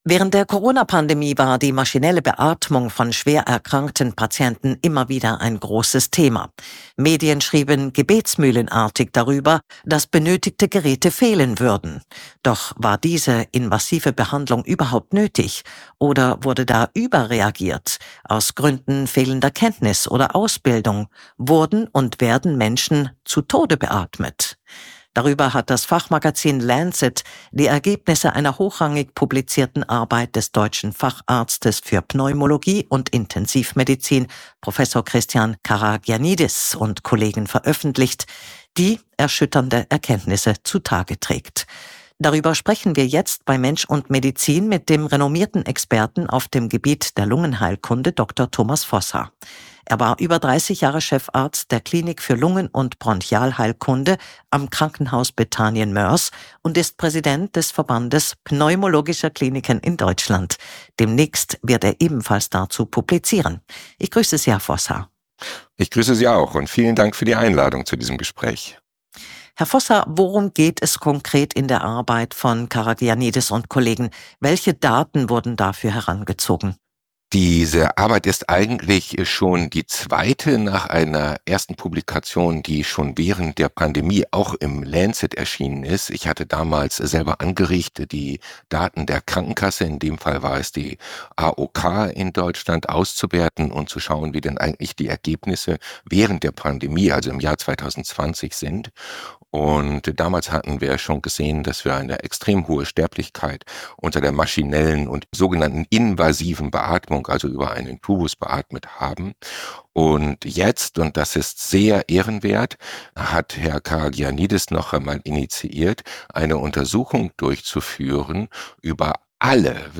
Kontrafunk-Interview